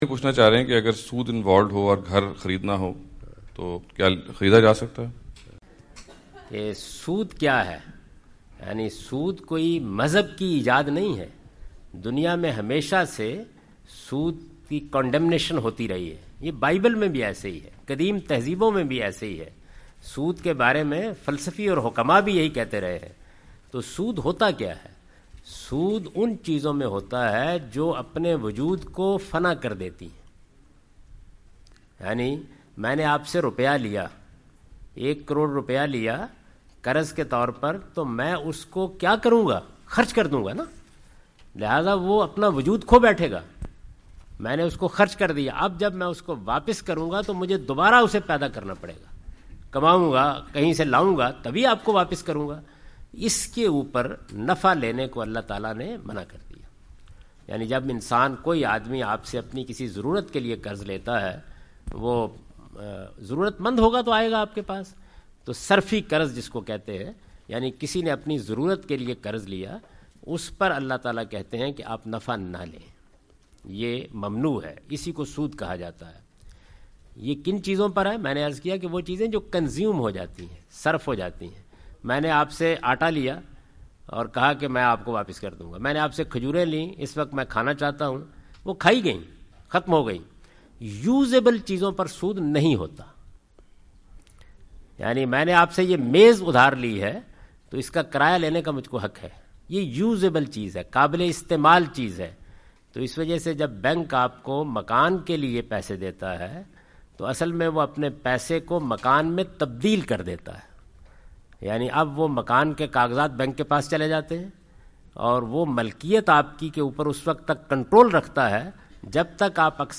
Category: English Subtitled / Questions_Answers /
جاوید احمد غامدی اپنے دورہ امریکہ کے دوران ڈیلس۔ ٹیکساس میں "قسطوں پر گھر خریدنا" سے متعلق ایک سوال کا جواب دے رہے ہیں۔